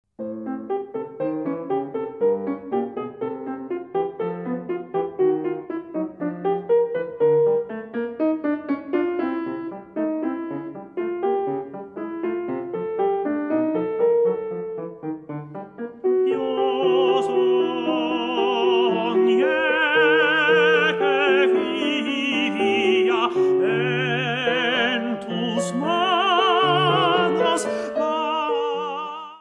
Neue Musik
Vokalmusik
Ensemblemusik
Duo
Tenor (1, oder Sopran), Klavier (1)